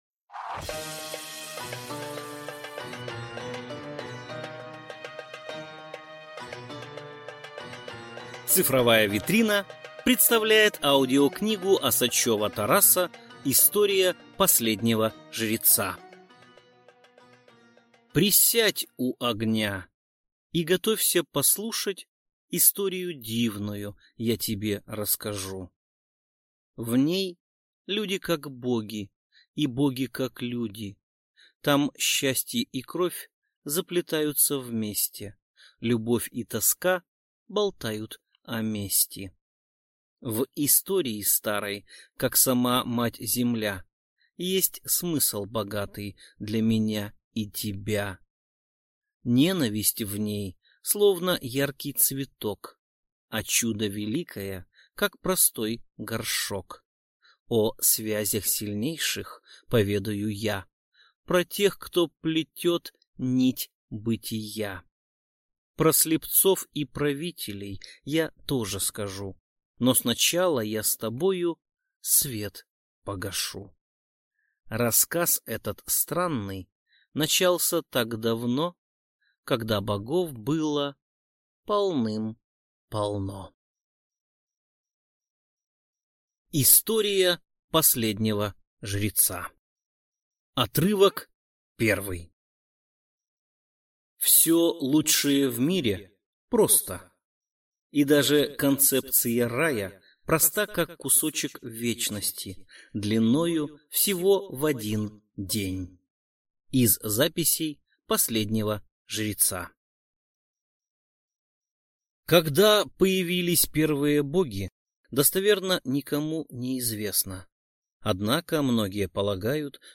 Аудиокнига История последнего Жреца | Библиотека аудиокниг